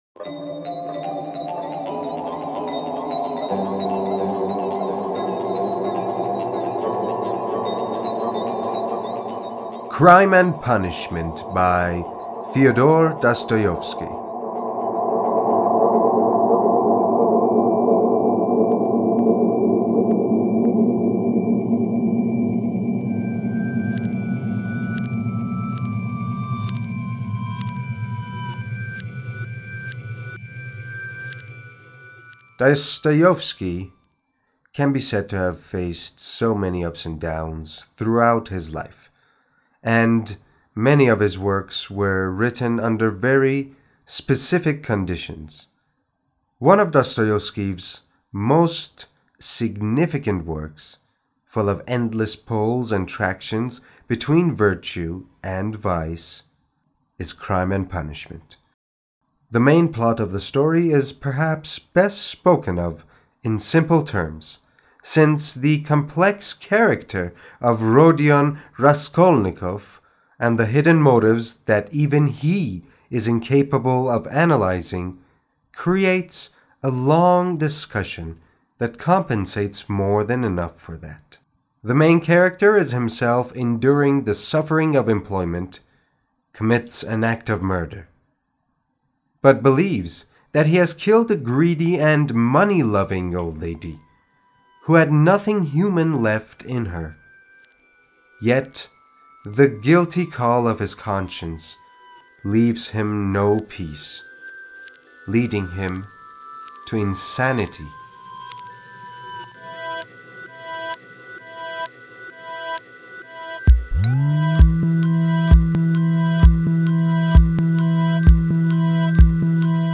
معرفی صوتی کتاب Crime and Punishment